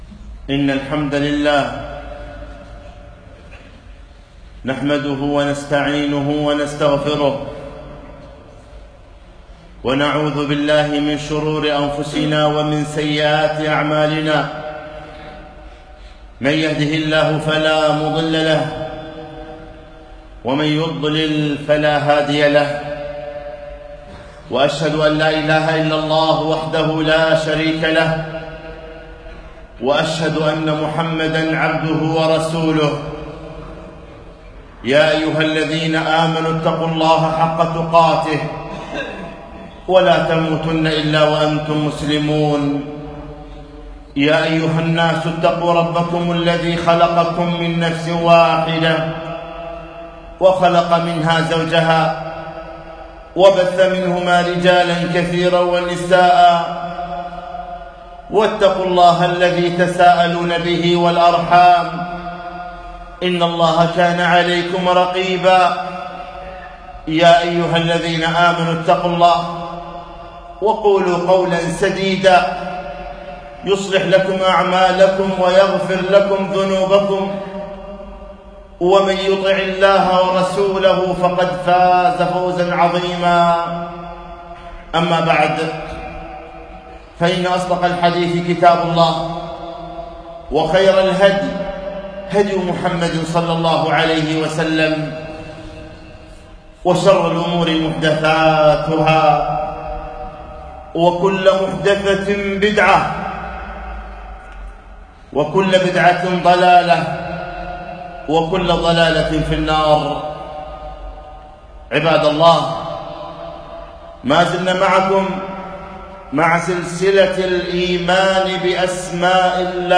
خطبة - تعريف الحشود بالرب المعبود